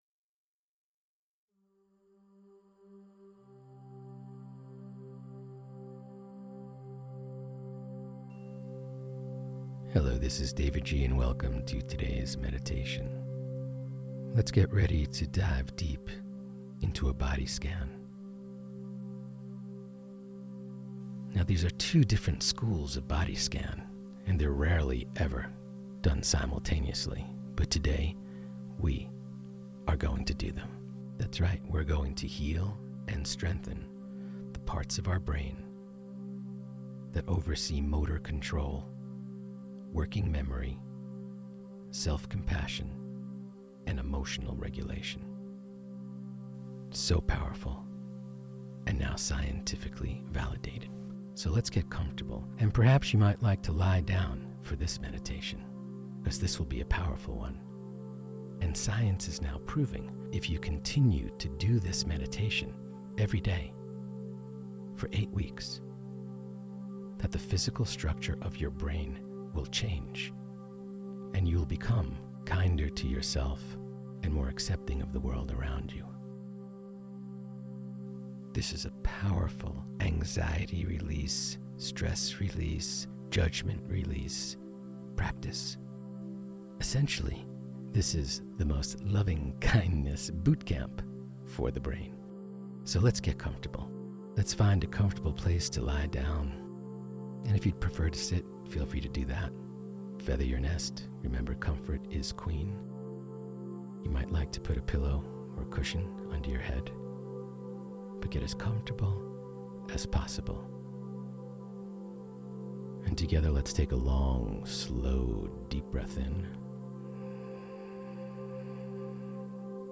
Join me for this powerful 'witness & accept' body scan. This meditation is based on research done by Massachusetts General Hospital studying how mindfulness meditation training changes brain structure in eight weeks.
Deep-Healing-Body-Scan-Audio-Lesson.mp3